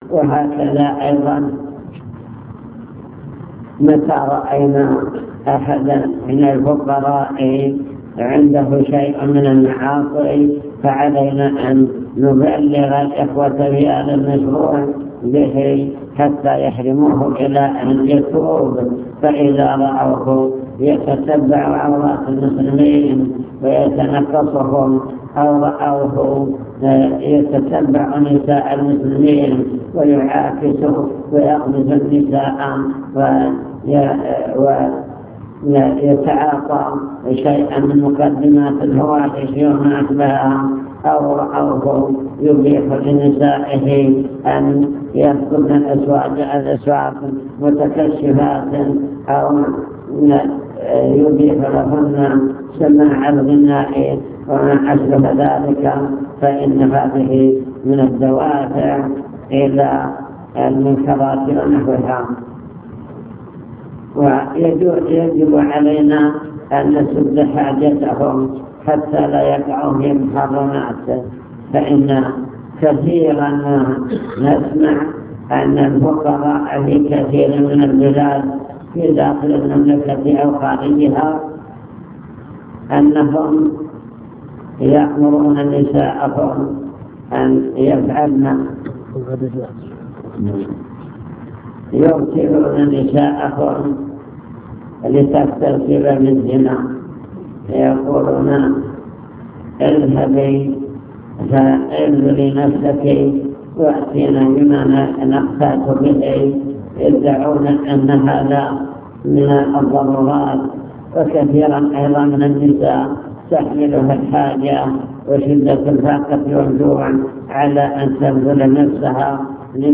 المكتبة الصوتية  تسجيلات - لقاءات  كلمة مجلس مستودع الأوقاف